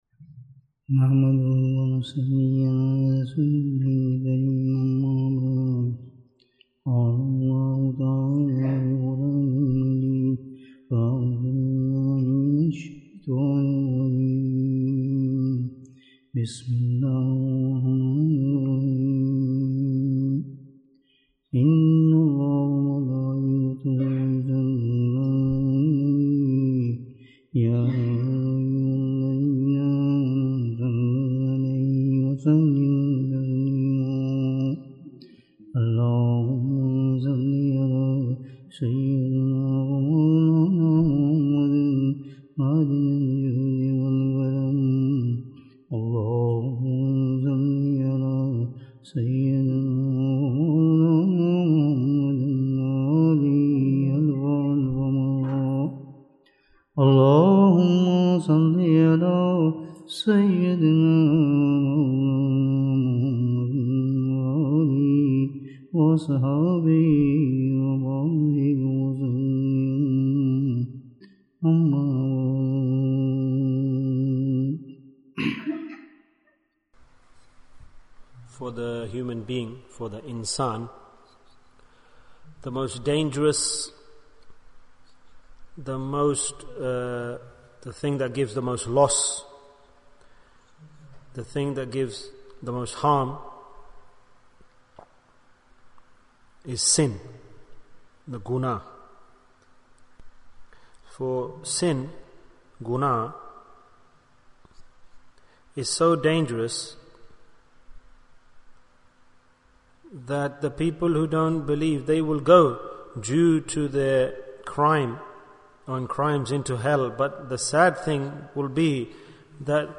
Why do we Sin? Bayan, 35 minutes23rd June, 2022